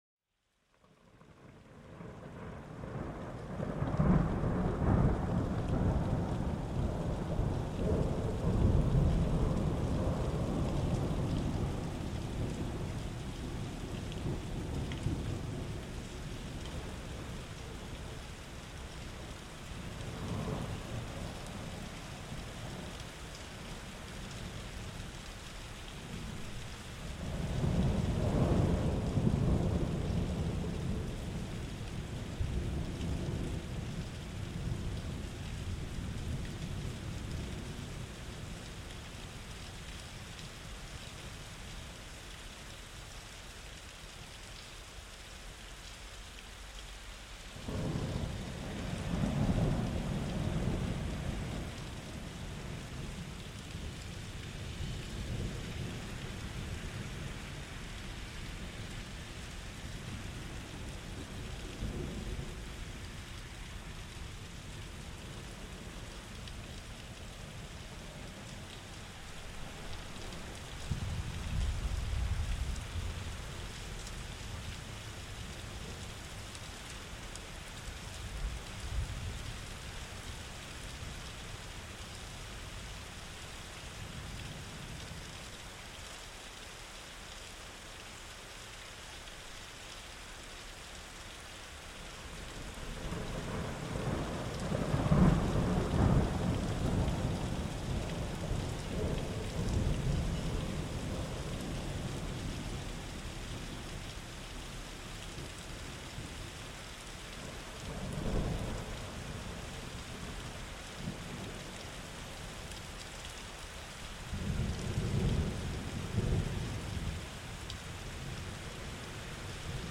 Tonnerre et pluie : apaisement à travers la force de la nature
Laissez-vous bercer par le grondement du tonnerre et le doux clapotis de la pluie. Ces sons puissants mais réconfortants recréent une atmosphère naturelle idéale pour se détendre.